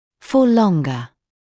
[fɔː ‘lɔŋgə][фоː ‘лонгэ]на более длительный срок; на более длительное время